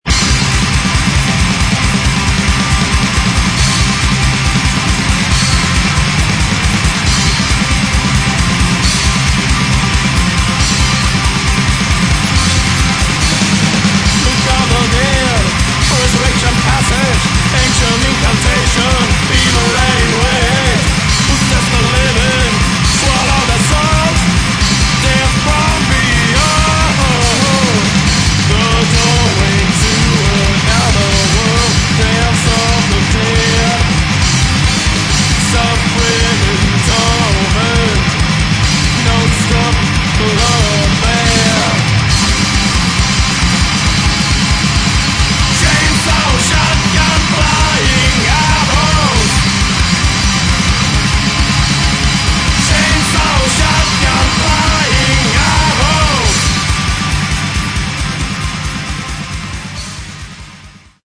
Metal
Очень тяжелая и жесткая музыка, с хорошим вокалом.